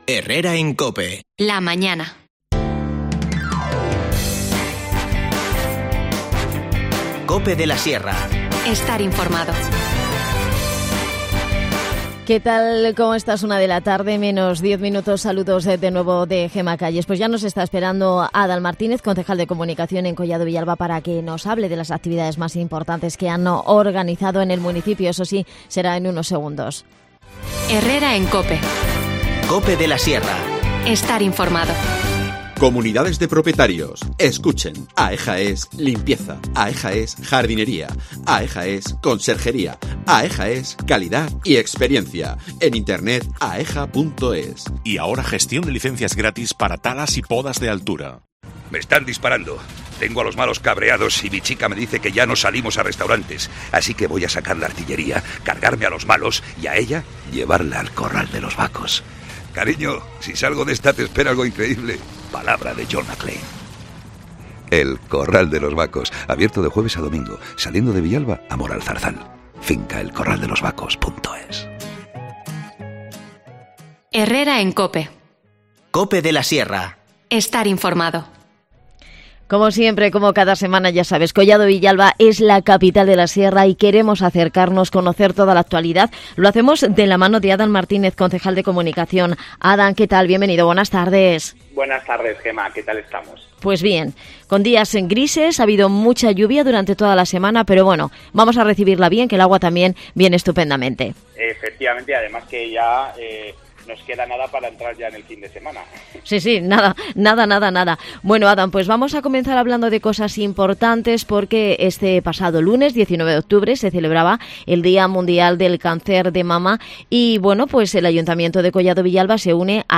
AUDIO: Adan Martínez, concejal de Comunicación en Collado Villalba, nos habla de las actividades que han organizado como la nueva edición del Club...